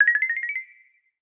Sound effect of Boom Boom Invisibility in Super Mario 3D World.
SM3DW_Boom_Boom_Invisible.oga